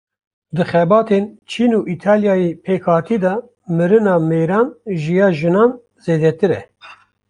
Read more China (a country in Asia) Frequency B2 Pronounced as (IPA) /t͡ʃʰiːn/ Etymology Cognate with Turkish Çin In summary Compare Turkish Çin.